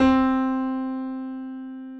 88鍵ピアノの、下から4番目のCの音に注目する
p-pitch-middleC.mp3